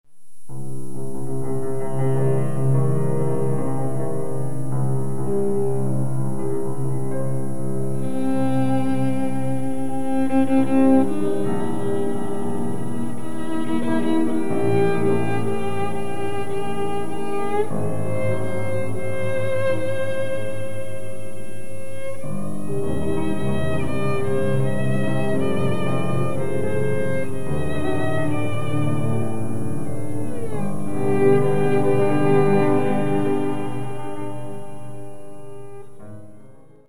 - chansons et danses hongroises: